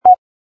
sfx_ui_react_error01.wav